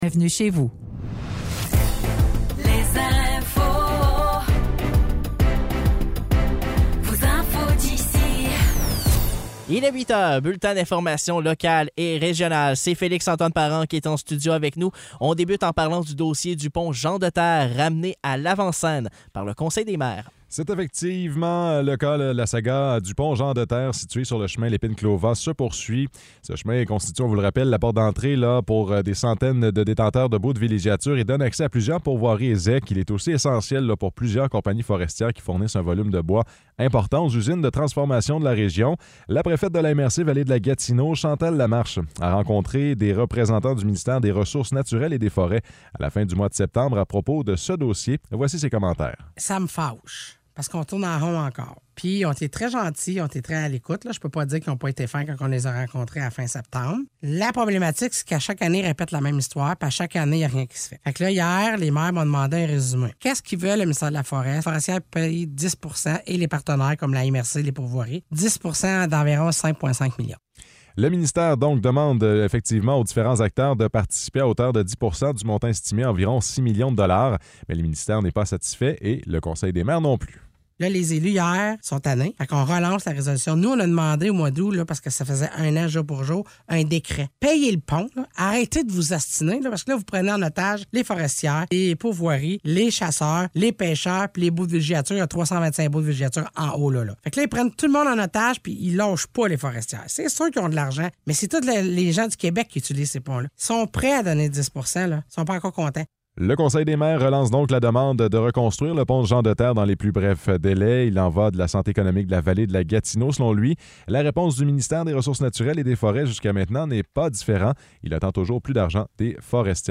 Nouvelles locales - 8 novembre 2023 - 8 h